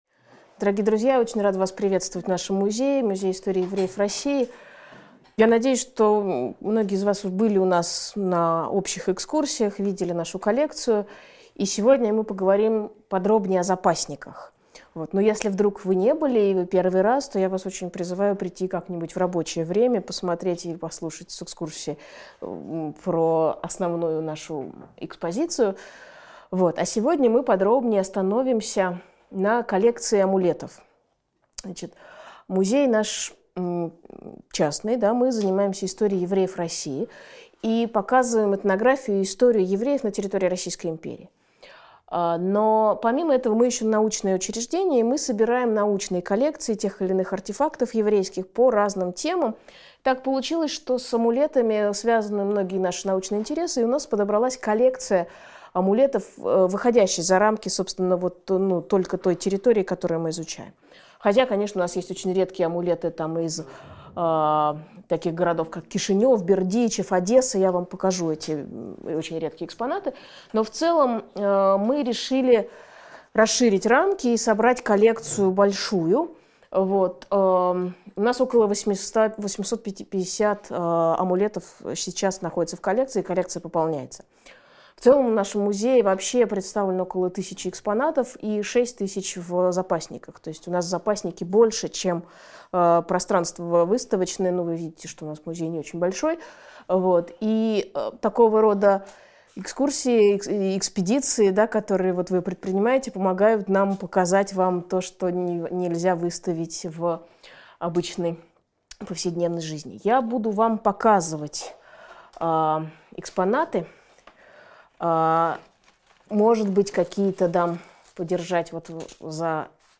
Аудиокнига Магические амулеты из Музея истории евреев | Библиотека аудиокниг